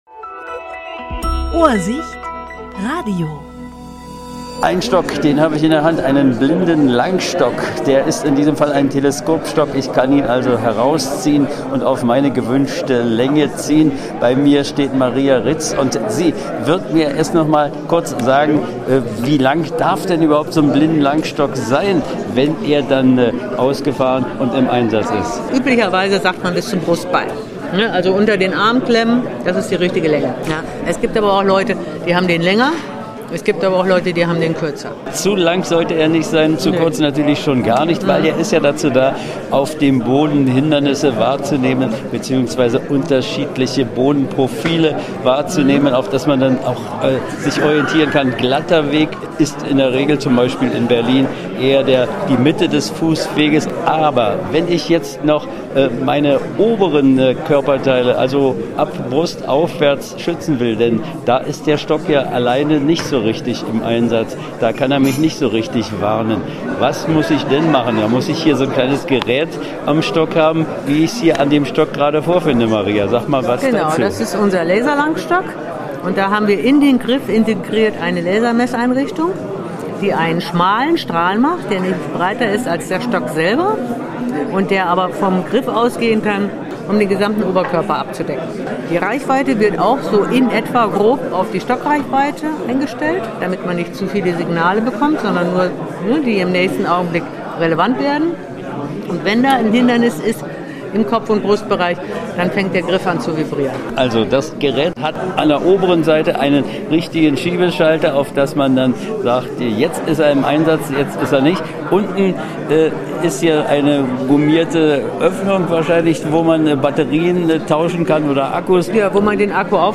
Interview 26.11.2025